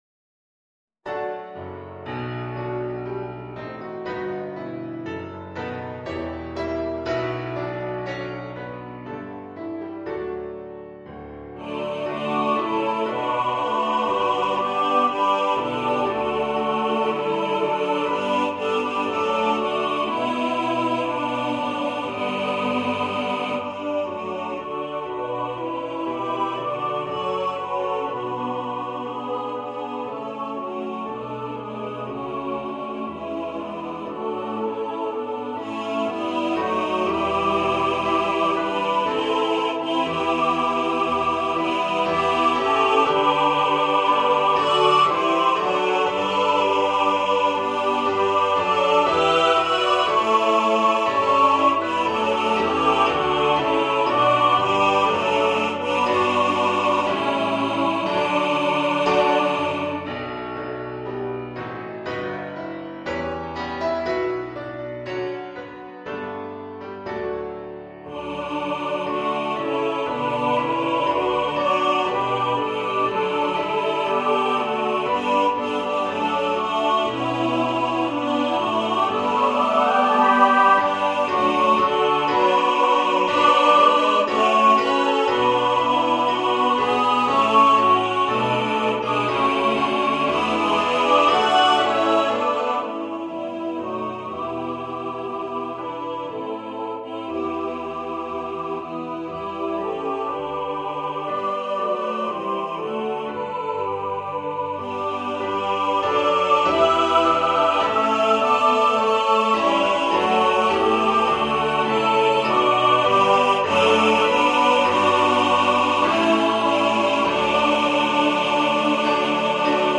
for SATB choir
Choir - Mixed voices (SATB)